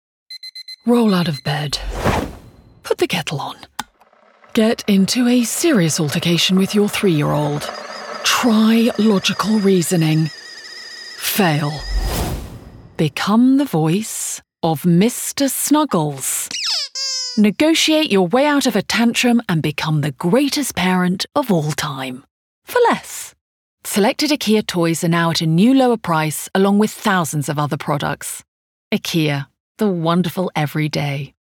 RP
Female
Assured
Bright
Dry
IKEA COMMERCIAL